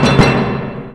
PIPE DRUM.wav